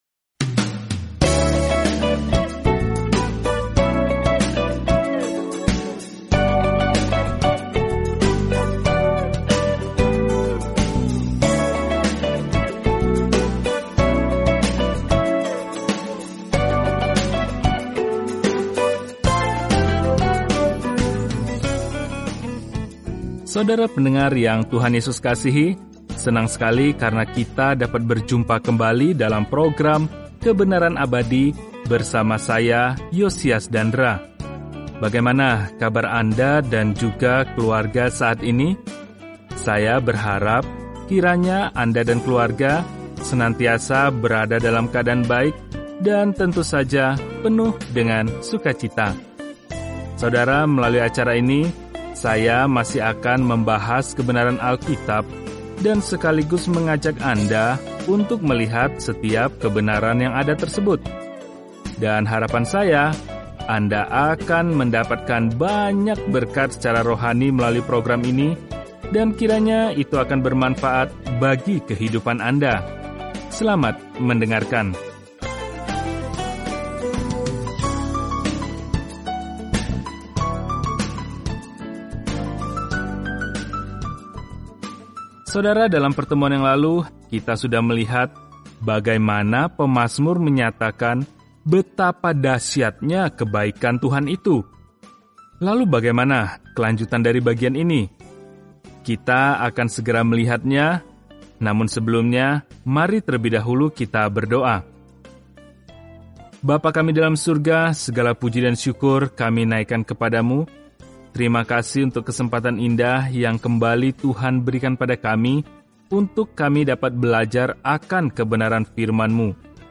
Firman Tuhan, Alkitab Mazmur 32 Mazmur 33 Hari 19 Mulai Rencana ini Hari 21 Tentang Rencana ini Mazmur memberi kita pemikiran dan perasaan tentang serangkaian pengalaman bersama Tuhan; kemungkinan masing-masing aslinya disetel ke musik. Bacalah Mazmur setiap hari sambil mendengarkan pelajaran audio dan membaca ayat-ayat tertentu dari firman Tuhan.